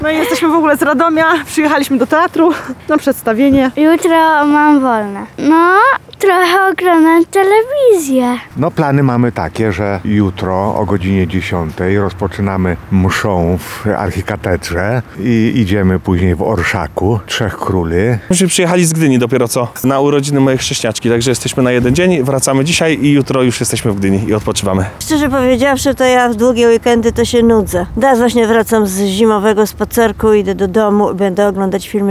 Reporterka Radia Lublin zapytała przechodniów, jakie mają plany na wydłużony styczniowym weekend.